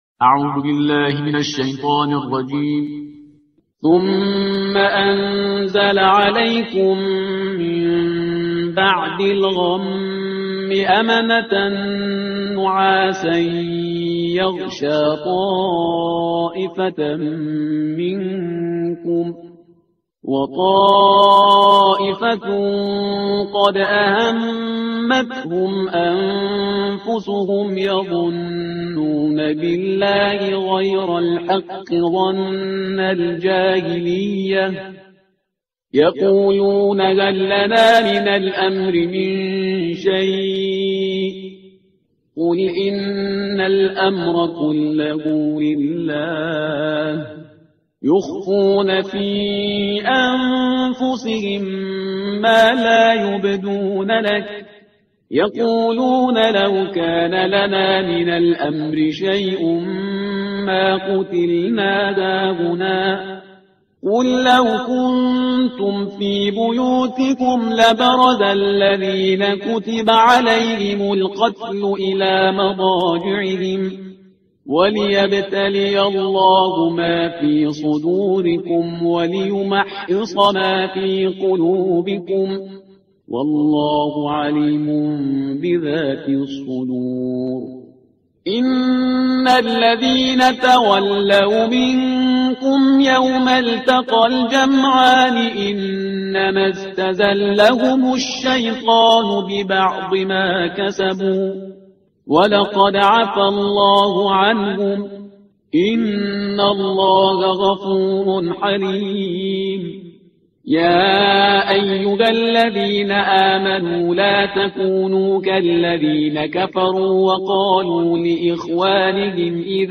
ترتیل صفحه 70 قرآن با صدای شهریار پرهیزگار